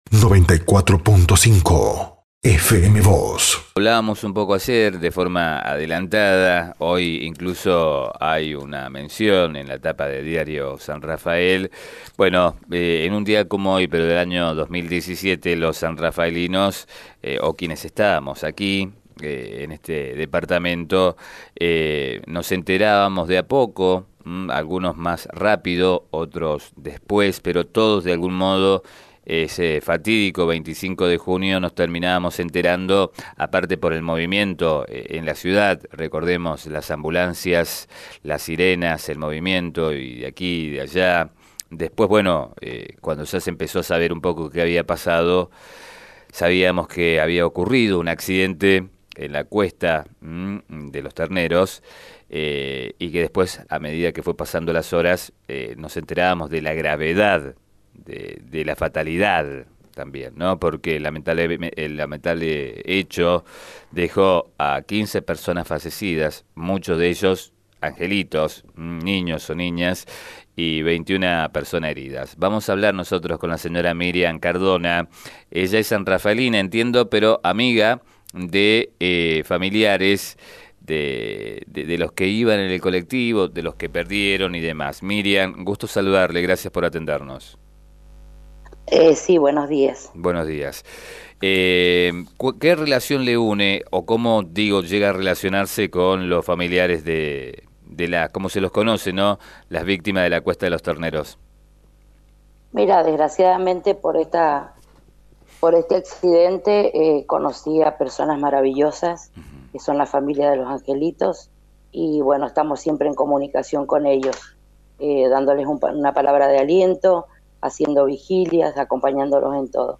En diálogo con FM Vos (94.5) y Diario San Rafael señaló que ella conoció de forma casual a esas familias, en las que encontró a muy buenas personas que debieron atravesar ese difícil momento.